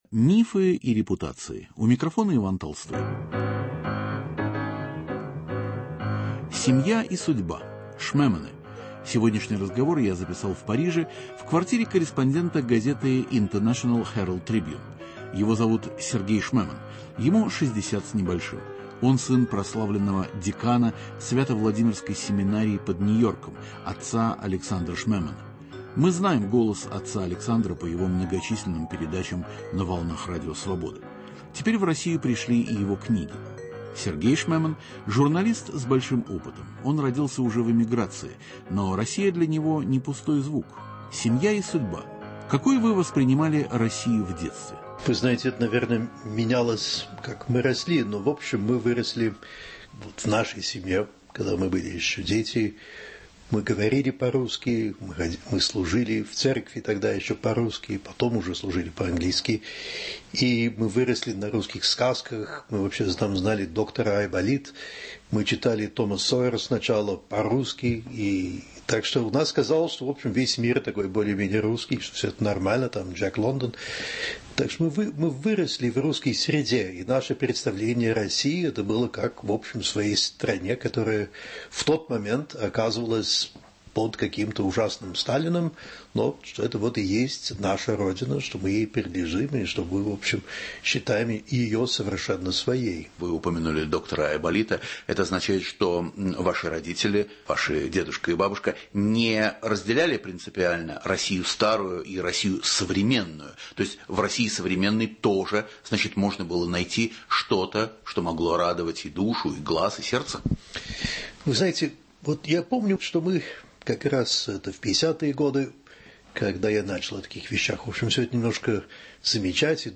Об истории рода, о России и чужбине, о журналистской работе в Москве в последние советские годы, а затем в эпоху Ельцина рассказывает Сергей Шмеман, лауреат Пулицеровской премии по журналистике. В программе звучит голос его отца - священника Александра Шмемана, многолетнего сотрудника Радио Свобода.